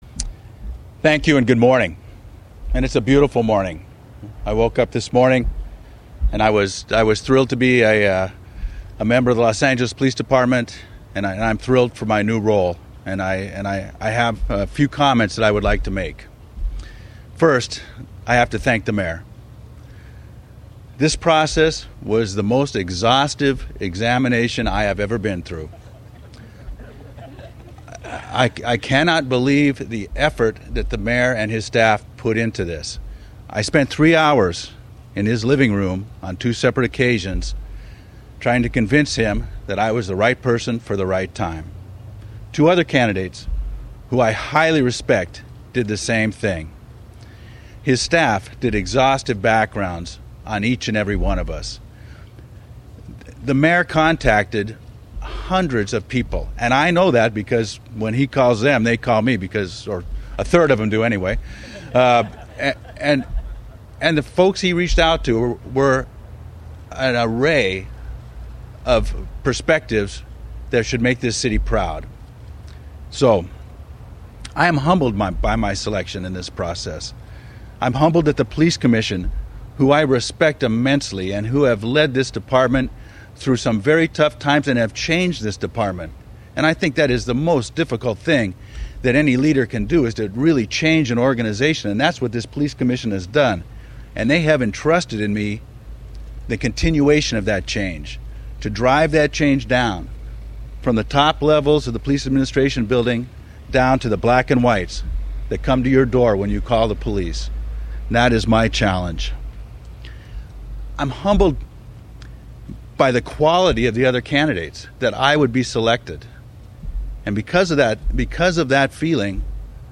November 3, 2024 - Audio of Deputy Chief Charlie Beck on being selected as the 55th Chief of the Los Angeles Police Department by Mayor Antonio Villaraigosa.